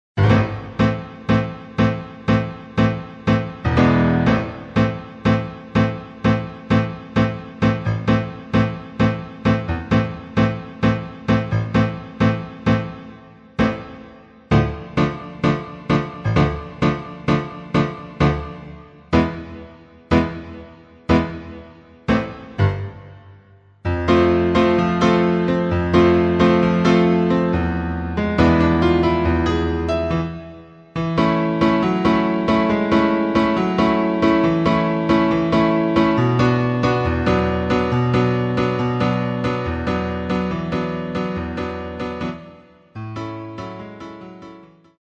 version piano seul